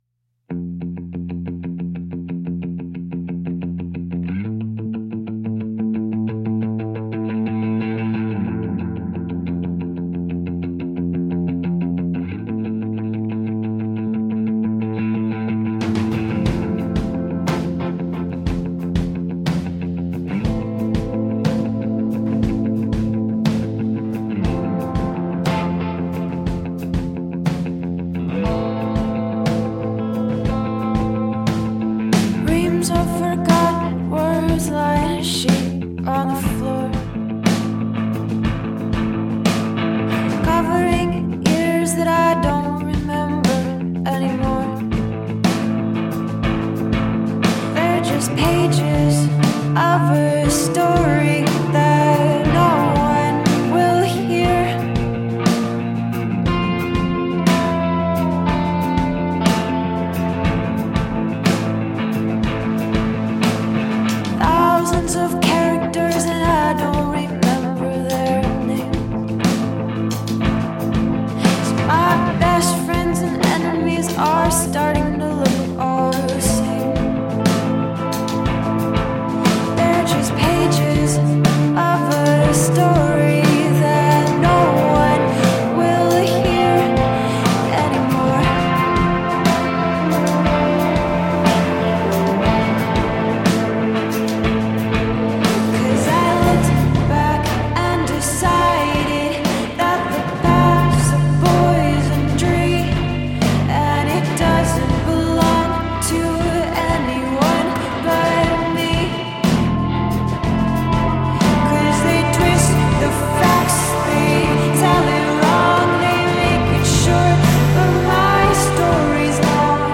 beautiful haunting alternative pop